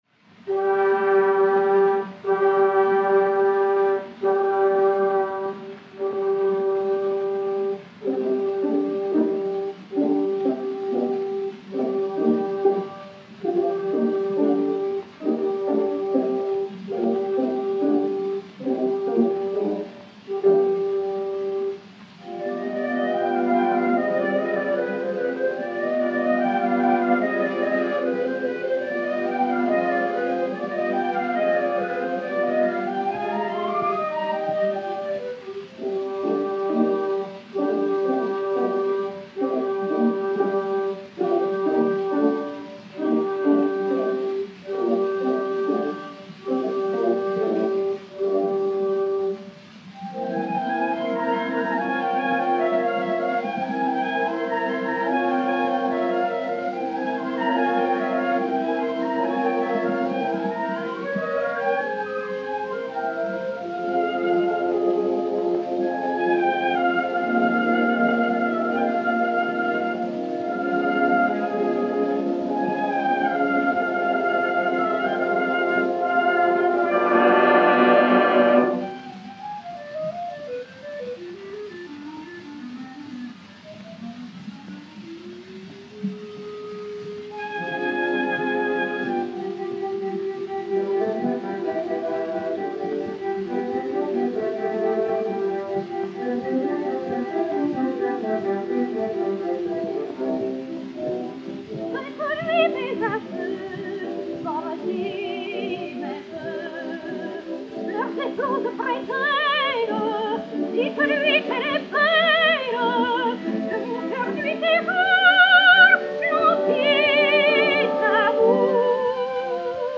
soprano français
extrait de l'intégrale de Faust de Gounod de 1912
Pathé saphir 90 tours mat. F.17 et F.18 (début), réédités sur 80 tours n° 1630, enr. en 1911/1912